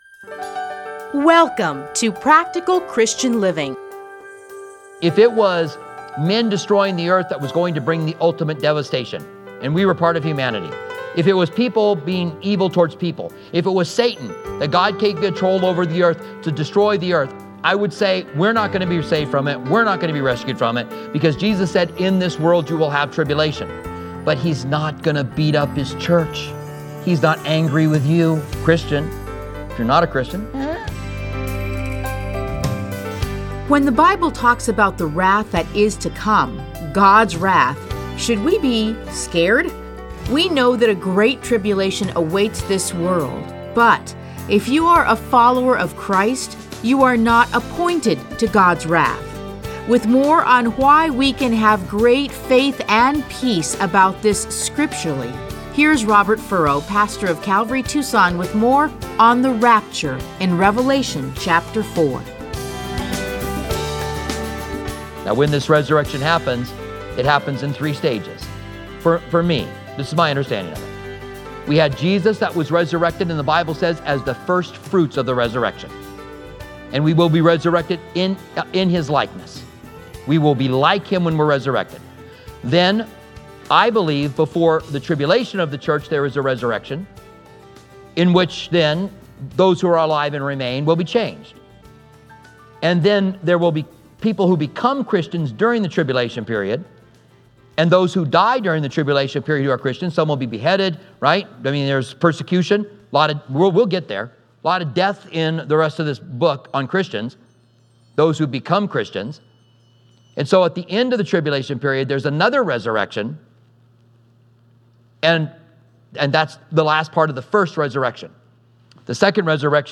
Listen to a teaching from Revelation 4:1-3.